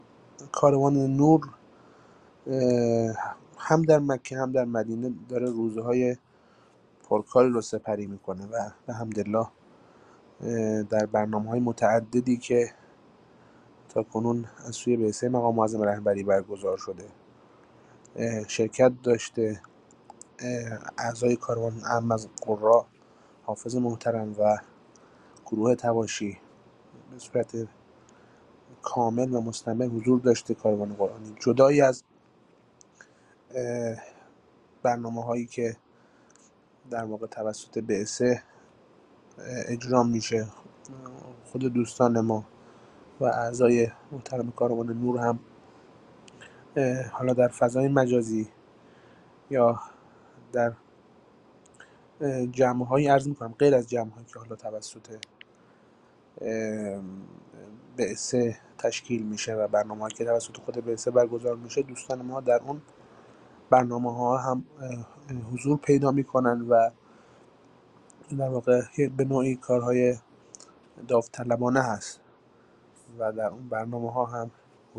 Рӯзҳои пурҷушу хуруши корвони Қуръонии Ҳаҷ; Зоирон аз қироати қориёни эронӣ истиқбол карданд + садо
Тегҳо: Корвон ، Қориён ، Тиловати Қуръон